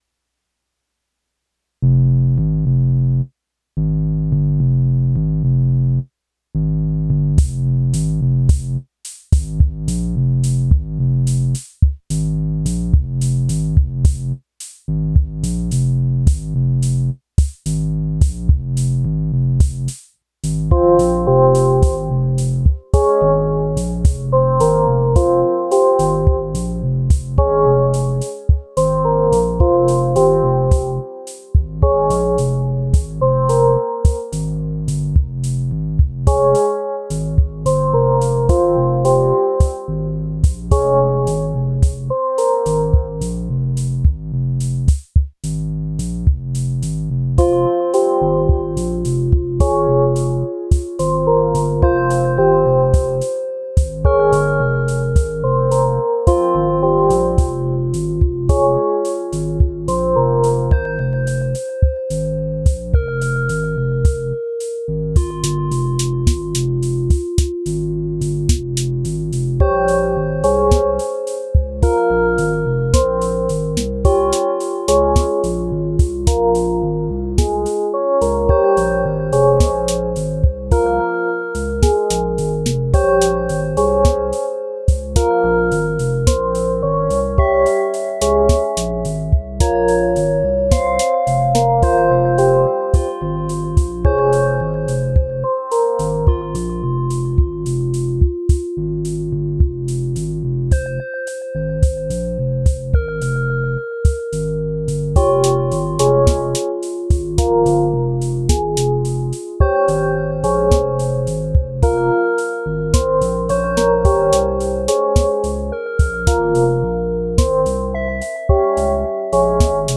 Another Deluge jam!
Mood: Intimate · BPM: 108 · Key: F Harmonic Minor · Color: Lavender